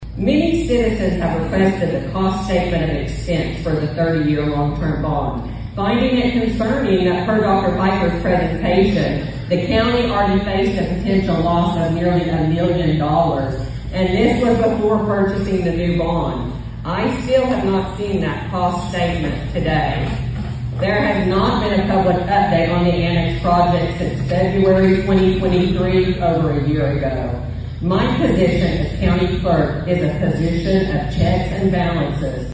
During Tuesday evening's Republican Forum in Osage County, County Clerk Robin Slack made some serious allegations against both former and current county commissioners.